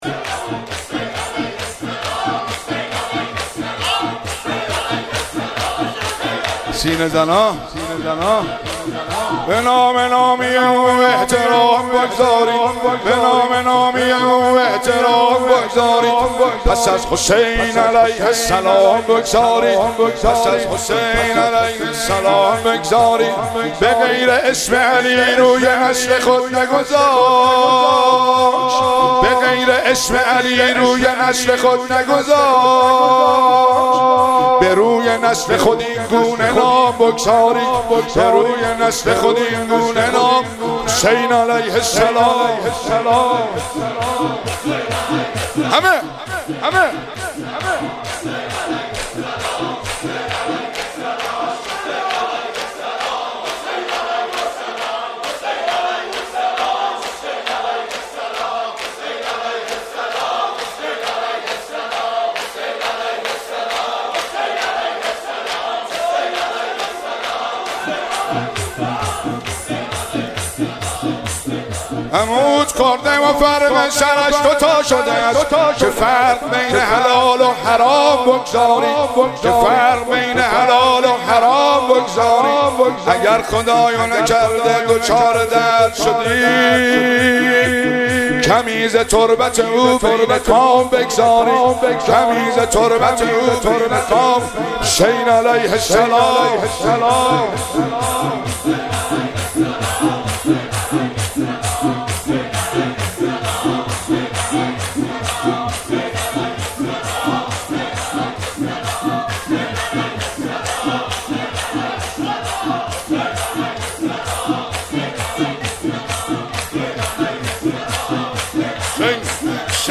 مراسم روضه اسارت اهل بیت (ع)- مرداد 1401
مداحان:
شور- به نام نامی او احترام بگذارید